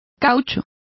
Complete with pronunciation of the translation of rubber.